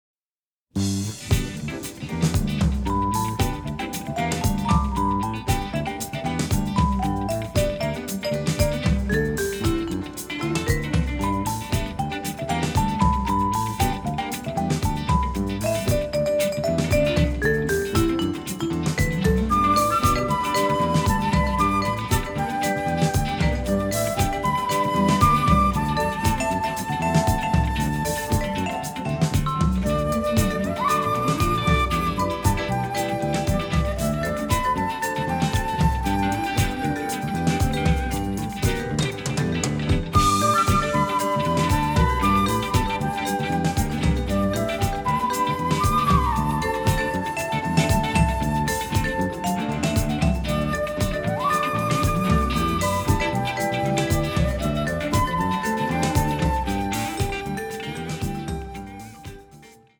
noir score
record the music in Paris